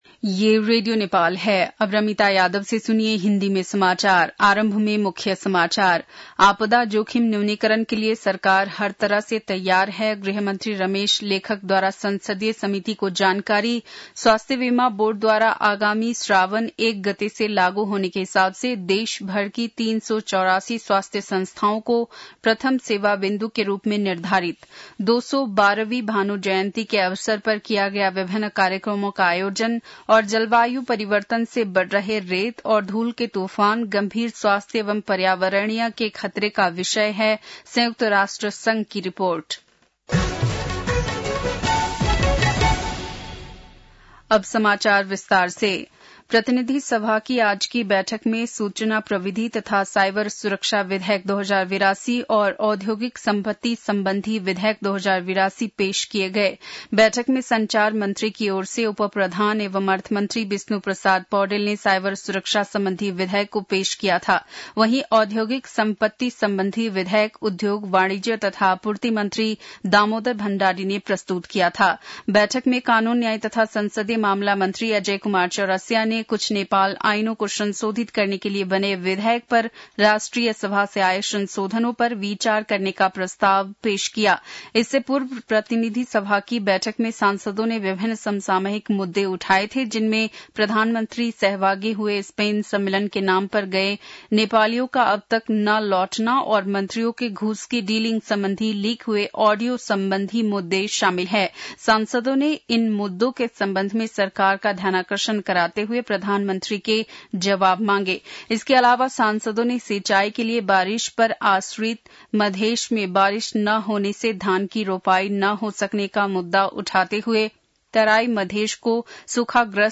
An online outlet of Nepal's national radio broadcaster
बेलुकी १० बजेको हिन्दी समाचार : २९ असार , २०८२
10-pm-hindi-news-3-29.mp3